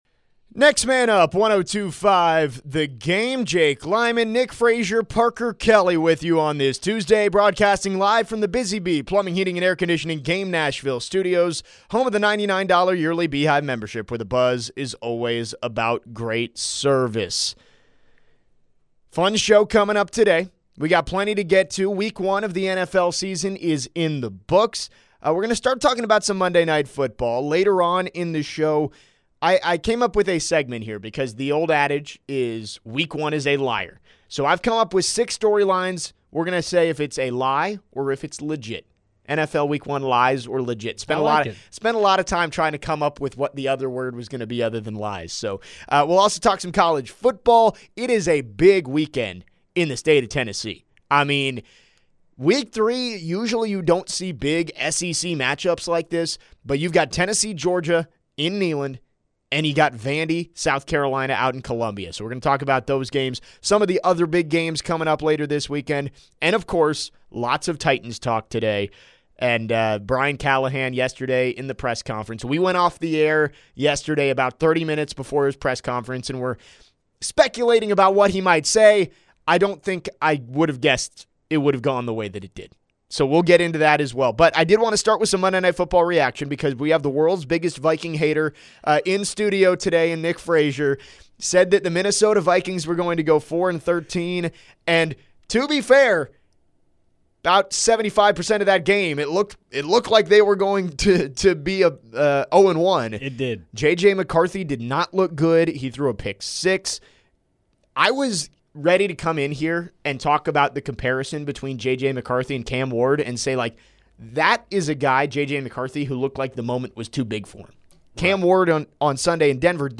in studio today. They discuss Brian Callahan's future with the Titans. Did he actually know the rule regarding the Elic Ayomanor non-catch on Sunday in Denver? Also, what do the Titans need to do to help Cam Ward?